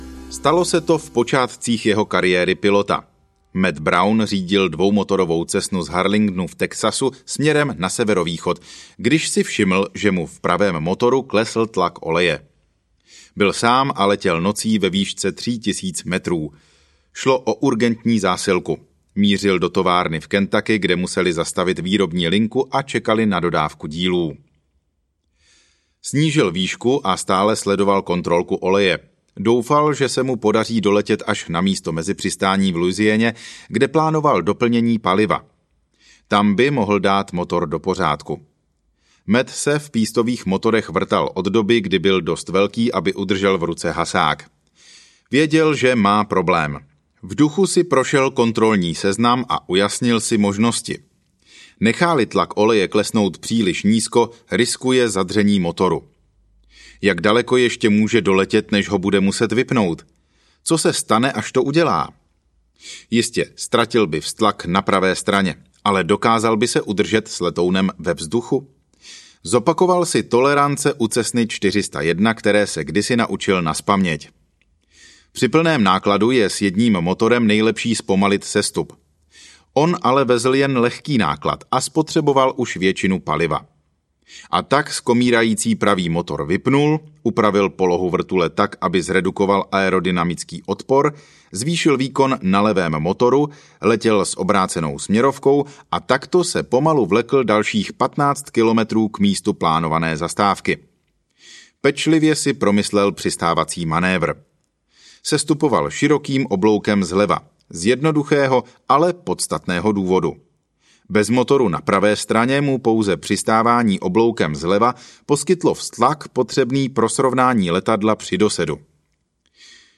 Nauč se to! audiokniha
Ukázka z knihy